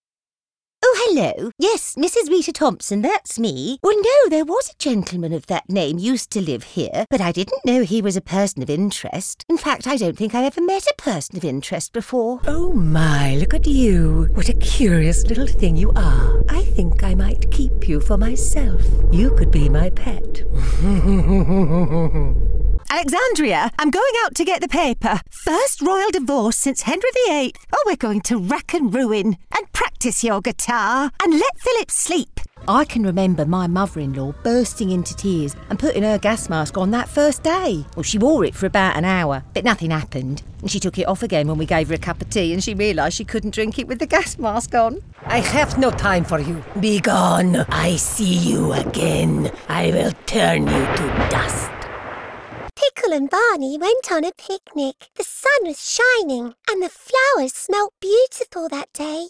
Velvety, smooth and sophisticated UK voice actor with a multitude of character voices!
Character Demo